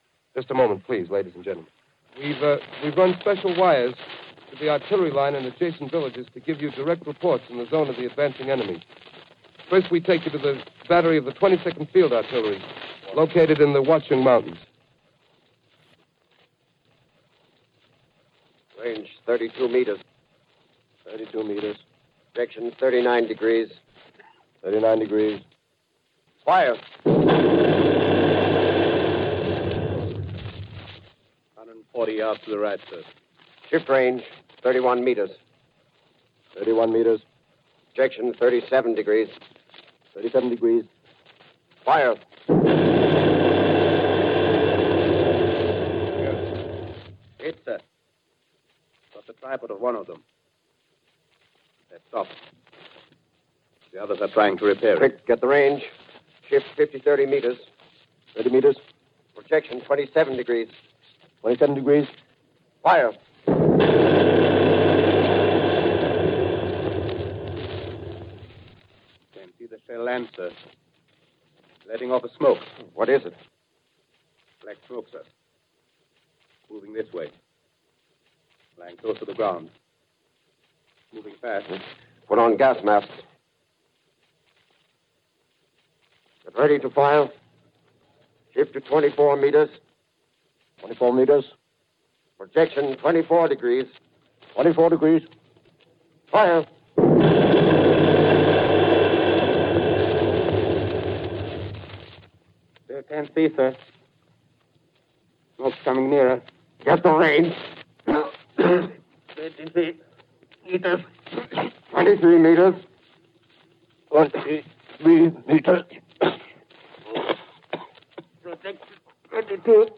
Radio Theatre: The War of The Worlds, Part Two (Audio)